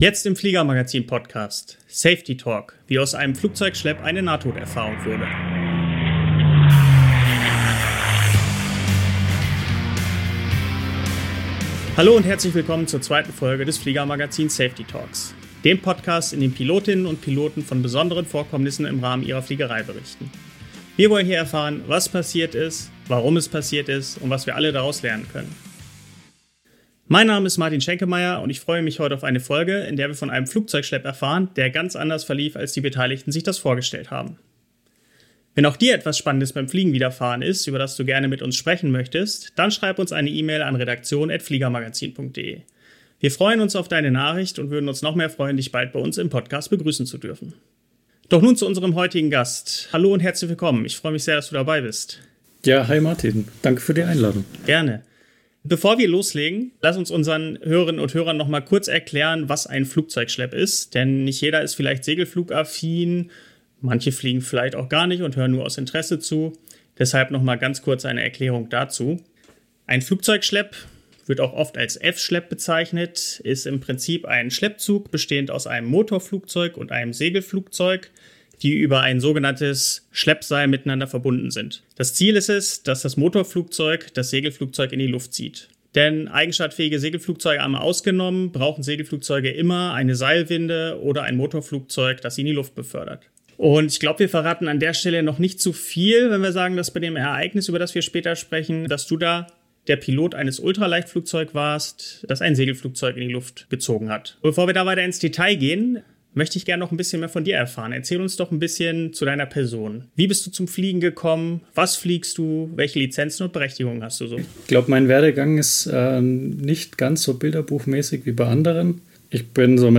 In Folge #02 erzählt der Pilot eines Schleppflugzeugs davon, wie das überhöht hinter ihm fliegende Segelflugzeug ihn fast in den Boden gezwungen hätte - und welche Folgen der Vorfall für ihn hatte.